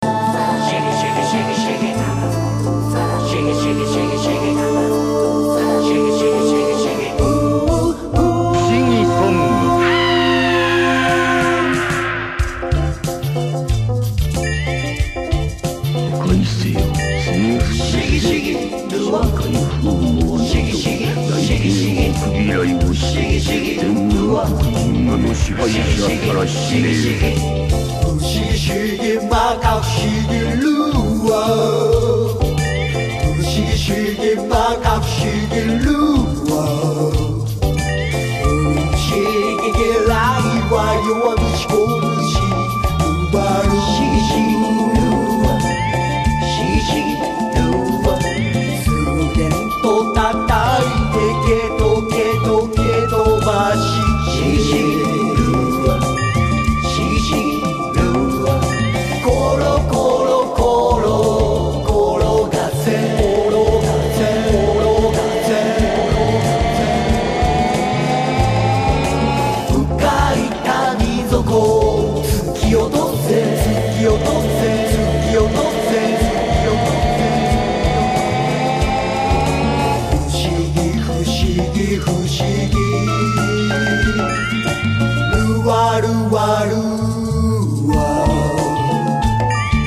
ともに酩酊感溢れる昨今の暑すぎる夏にピッタリのフロア爆弾です。